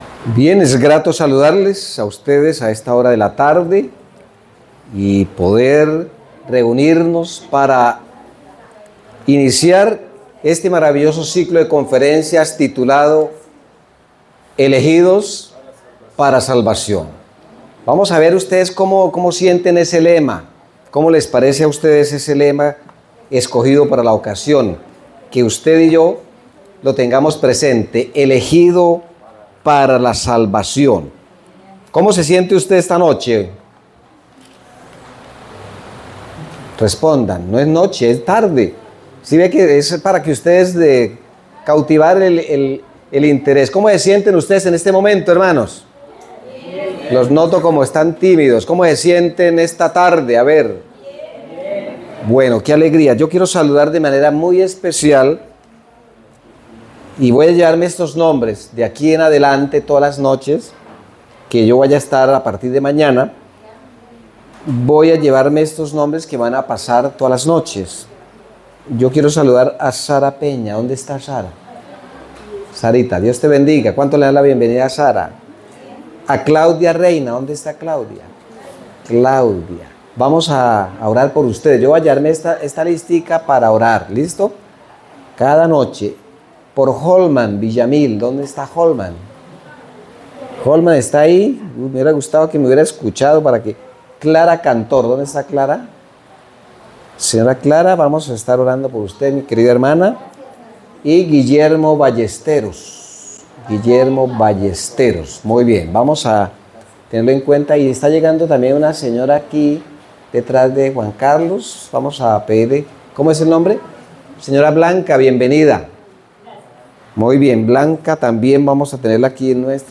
En esta semana de Conferencias podremos conocer diferentes ciudades que aparecen en la biblia y que nos servirá para comprender como Dios nos ha Elegido para Salvación.